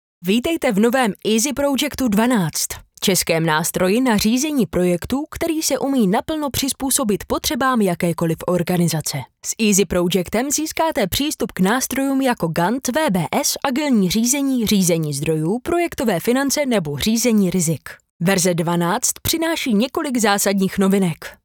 Ženský reklamní voiceover do jedné minuty
Natáčení probíhá v profesiálním dabingovém studiu.